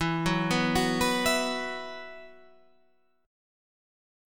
E Suspended 2nd